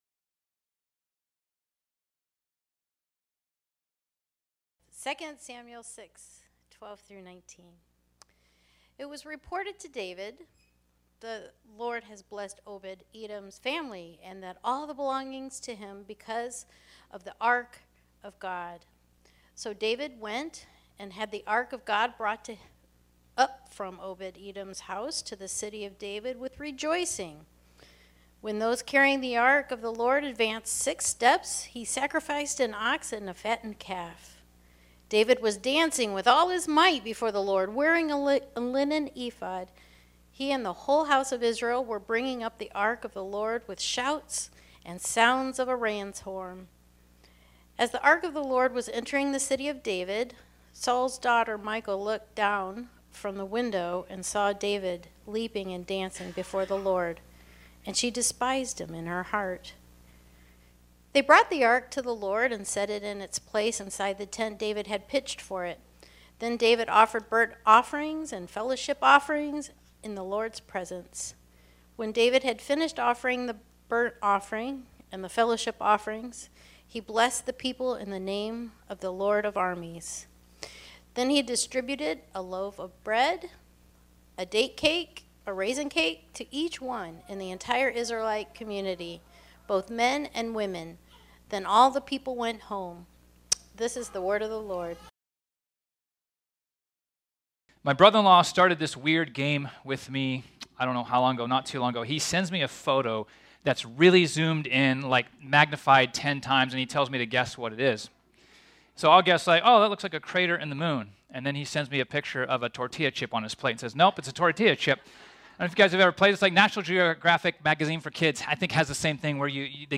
This sermon was originally preached on Sunday, July 9, 2023.